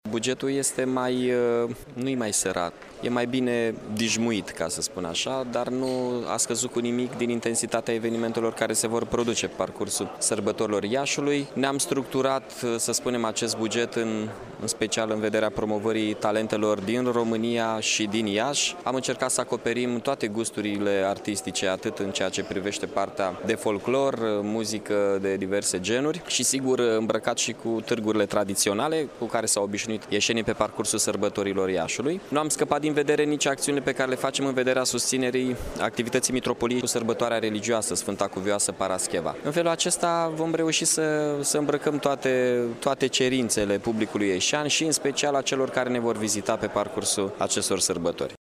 Primarul Mihai Chirica: